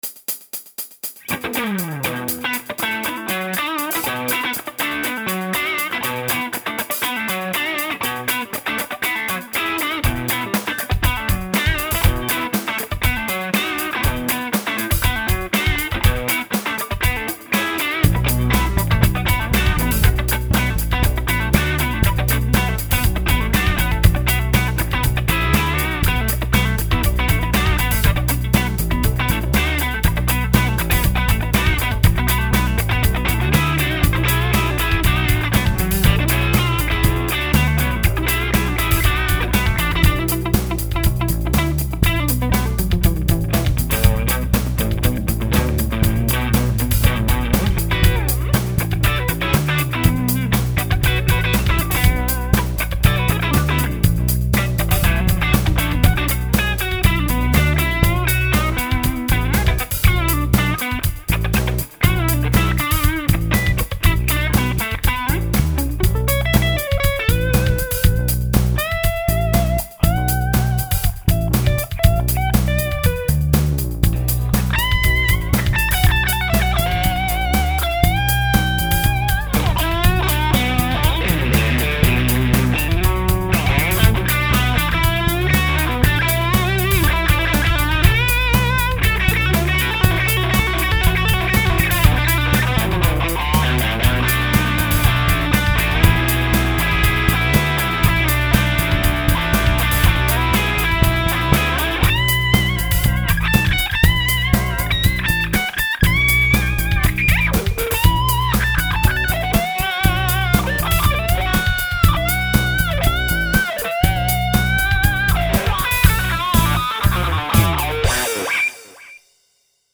Je vous ai fait un petit truc rapide qui sonne funky. Le matos est le suivant: strato classic 60 (micros Van zandt Blues model) -> Cry baby -> FatBoost2 -> OCD -> ampli Blues Deluxe Fender.
J'enregistre avec un micro statique schoeps cmc4 (à 20 cm de l'ampli) dans Protools (digi001). Le seul effet que j'utilise est un delay electro harmonix memoryman.
Jusque 1mn14, je n'utilise que l'OCD. Ensuite, j'enclenche la FB2 puis la cry baby sur la fin.
super samples, ca a l'air de bien fonctionné le fat boost, ca respecte bien la OCD, j'ai l'impression que ca colore très legerement les aigus.